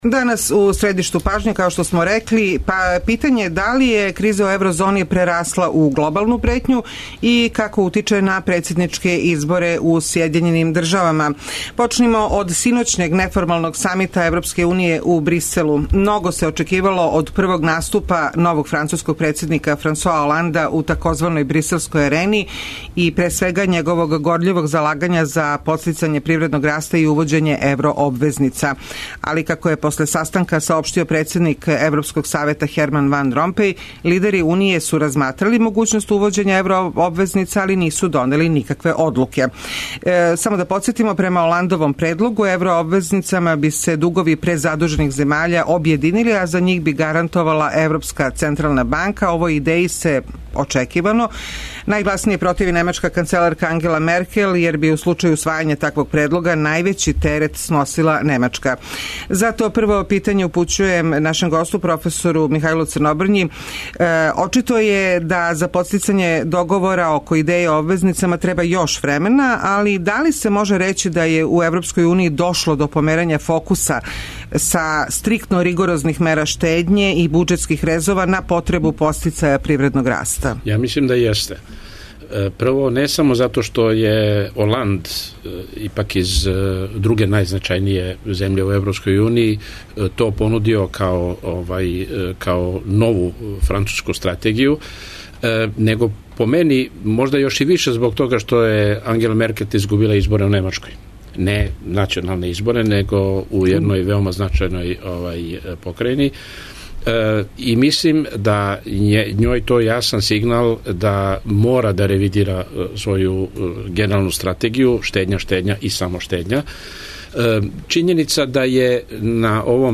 доноси интервју са нашим најбољим аналитичарима и коментаторима, политичарима и експертима